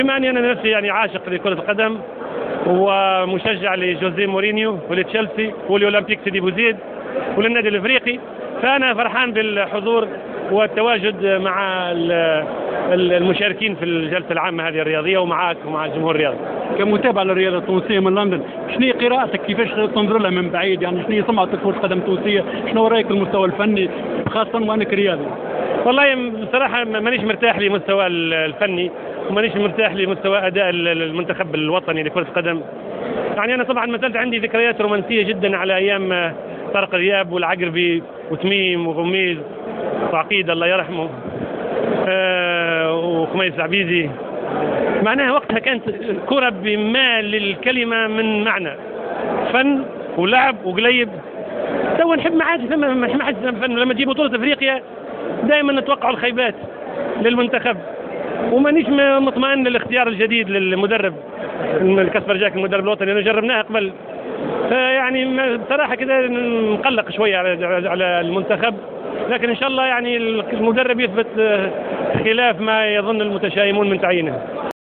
تحدث الهاشمي الحامدي لجوهرة أف أم على هامش حضوره في الجلسة العامة الخارقة للعادة للجامعة التونسية لكرة القدم حيث أعرب أنه من عشاق رياضة كرة القدم.